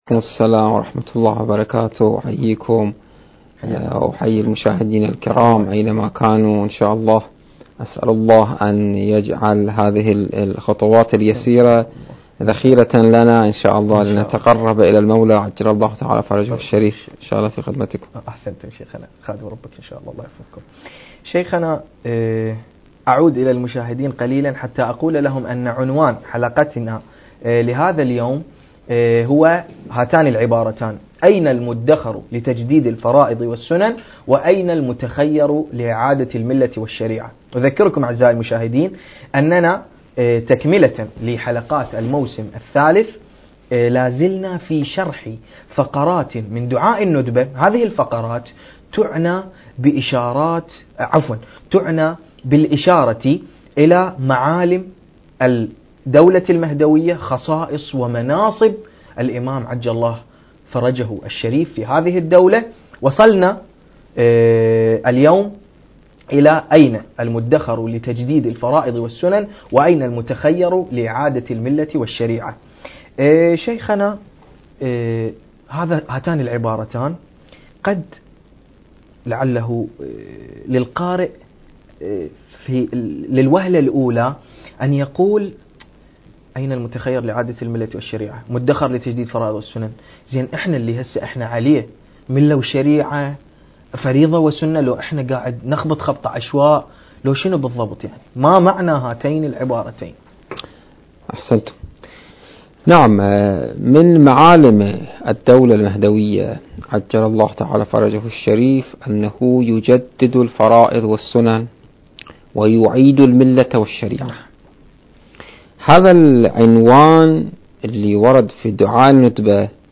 الحجم 6.55 MB محاور الحلقة: - من هو المتخصص؟ المكان: مؤسسة المجتبى (عليه السلام) للثقافة والإرشاد التاريخ: 2020